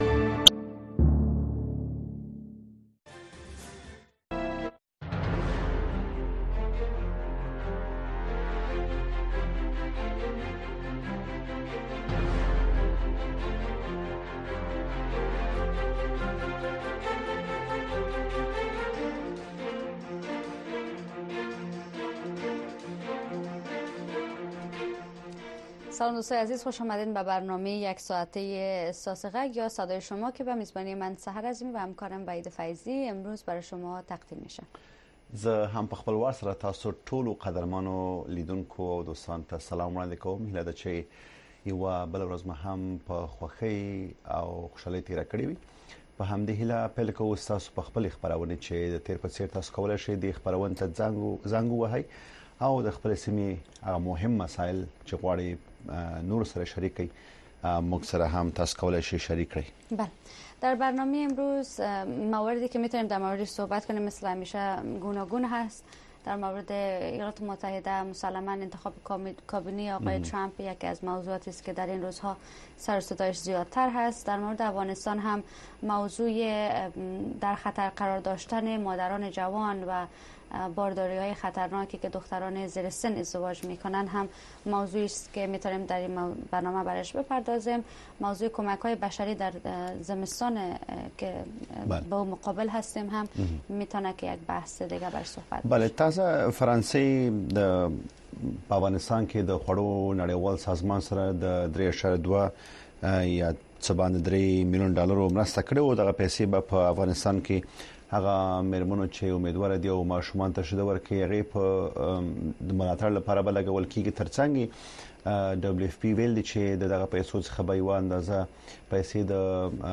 دا خپرونه په ژوندۍ بڼه د افغانستان په وخت د شپې د ۹:۳۰ تر ۱۰:۳۰ بجو پورې خپریږي.